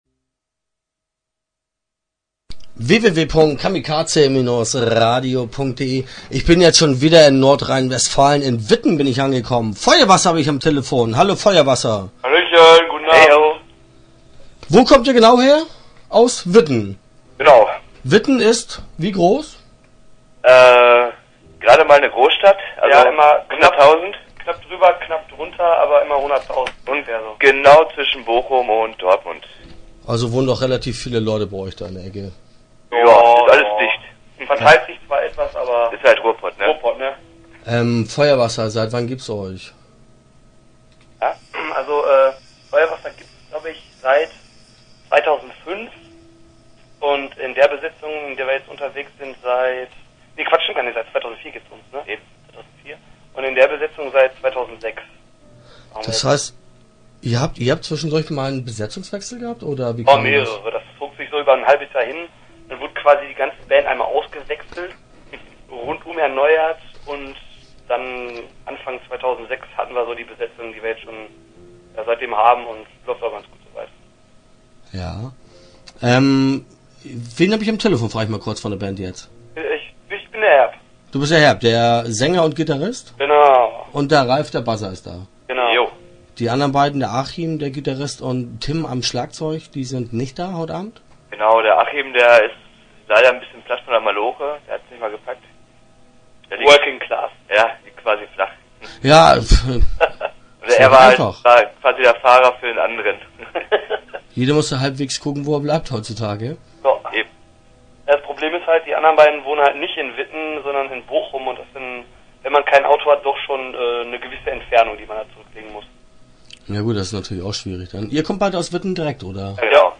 Start » Interviews » Feuerwasser